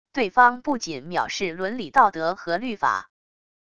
对方不仅藐视伦理道德和律法wav音频生成系统WAV Audio Player